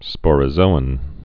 (spôrə-zōən)